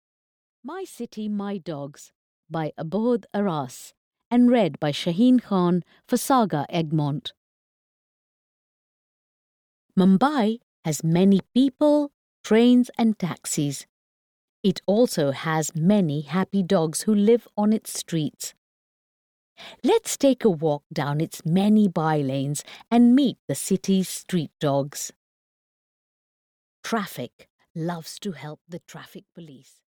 My City, My Dogs (EN) audiokniha
Ukázka z knihy